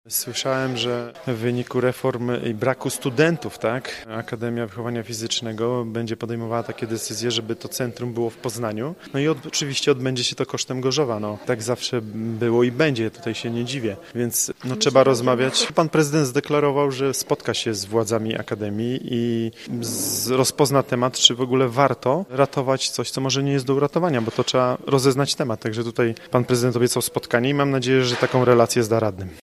Przewodniczący Rady Miasta z PiS Sebastian Pieńkowski liczy na rzetelną relację z tego spotkania: